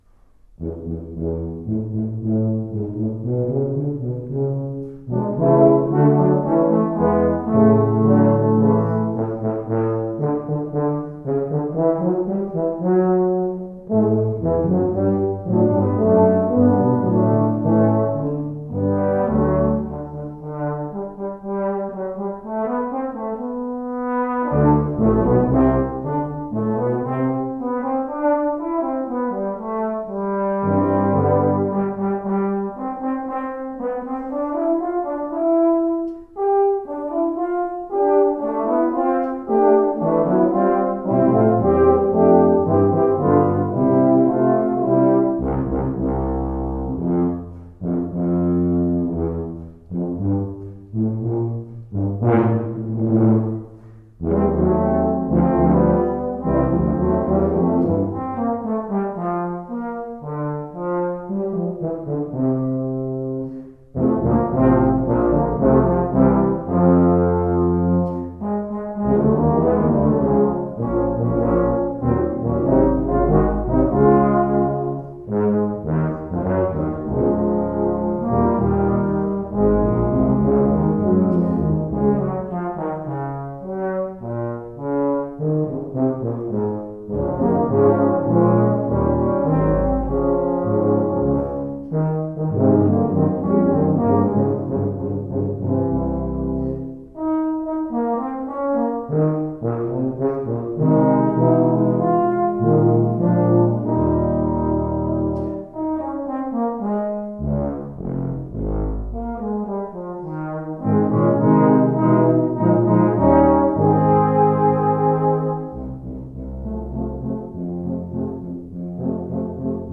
For Tuba Quartet (EETT), Composed by Traditional.